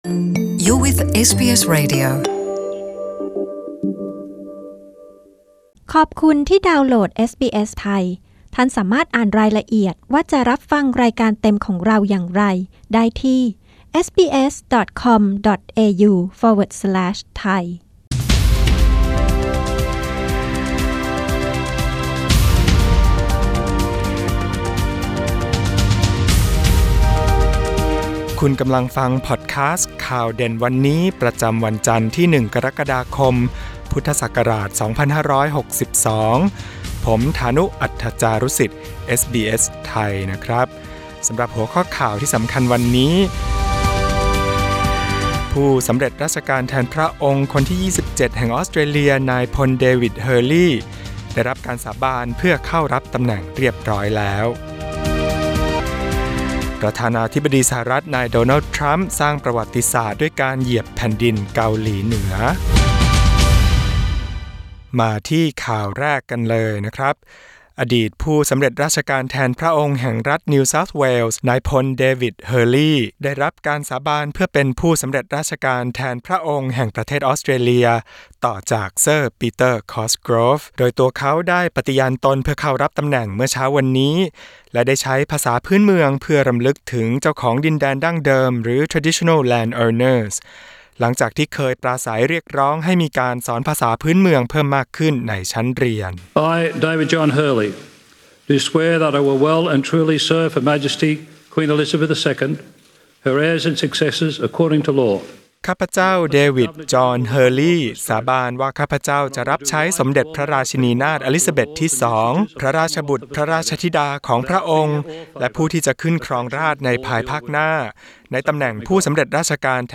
Press (▶) button to listen to Today's news bulletin in Thai Share